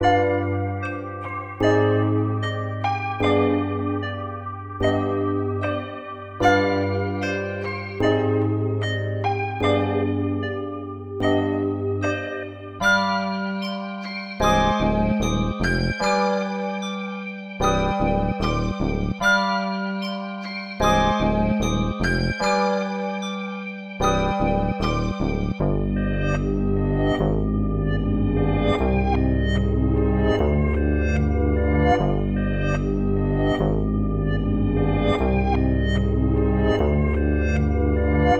limited_love_75bpm_oz.wav